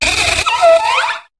Cri de Cheniselle dans Pokémon HOME.